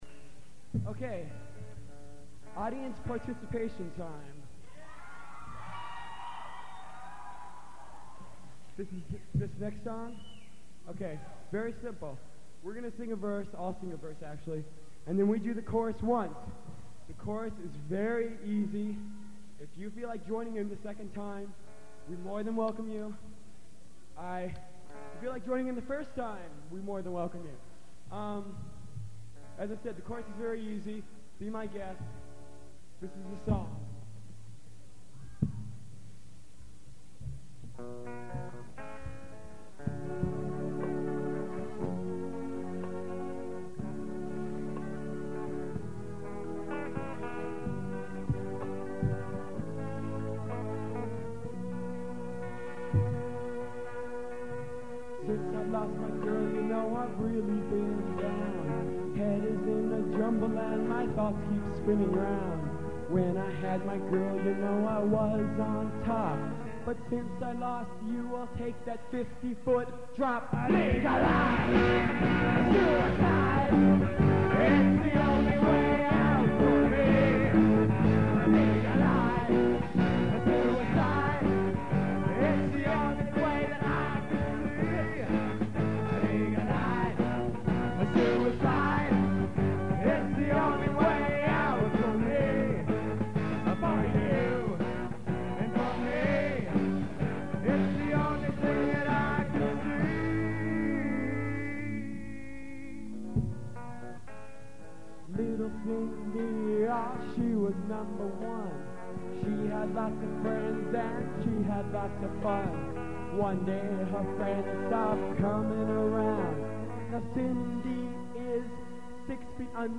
The audience of mostly teenagers loved it.